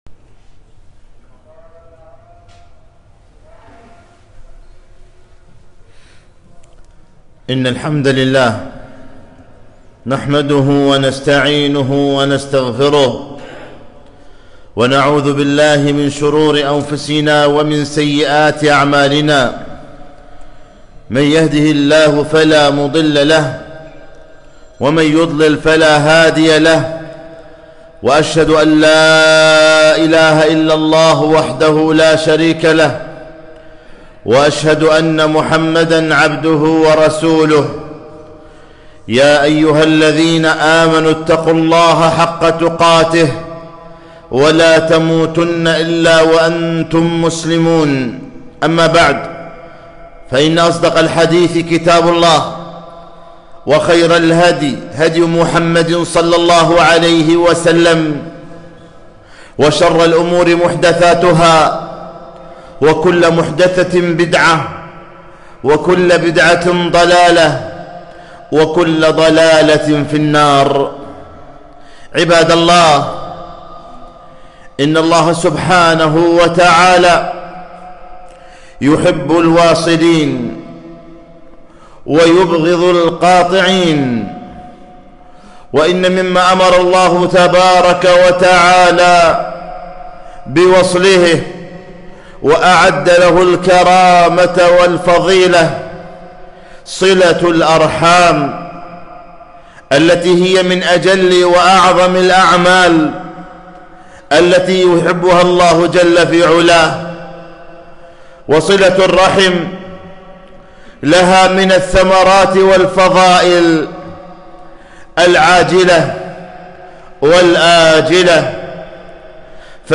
خطبة - يا قاطع الأرحام